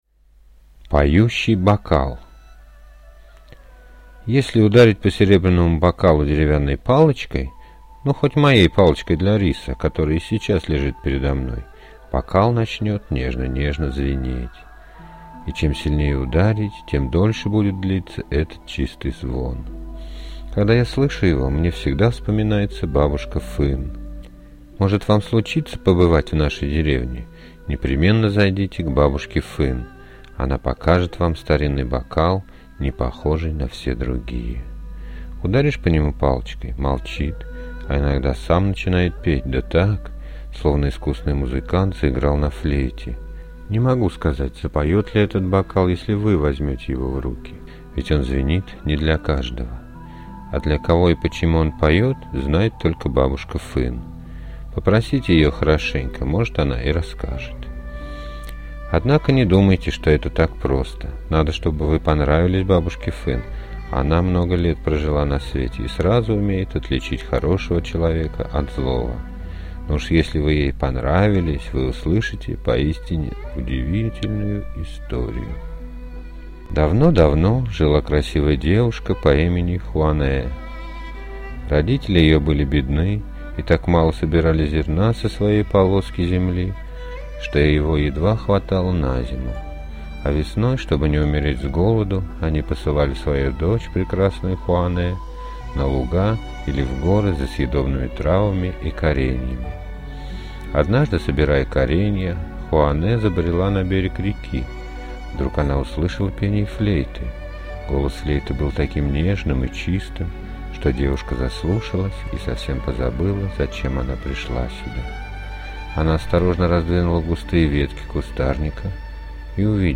Поющий бокал – китайская аудиосказка